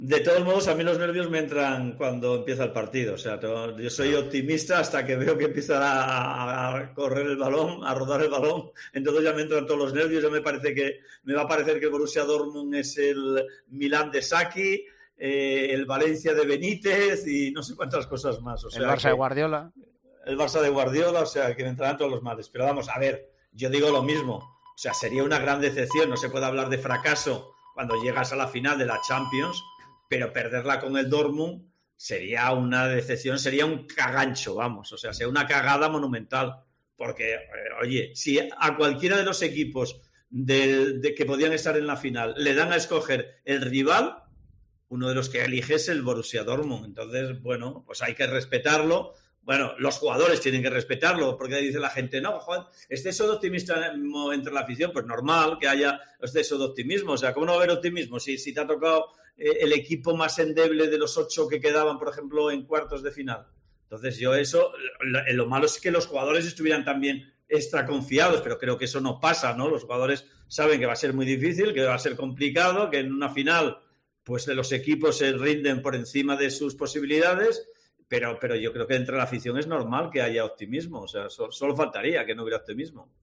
La cuenta atrás para la final de la Champions League ya ha comenzado y son muchos los que están viajando a Londres para estar en Wembley. Siro López es uno de ellos y en plena parada en Barcelona explicó en El Partidazo de COPE lo que le pone nervioso ante un partido de este tipo con la Liga de Campeones en juego.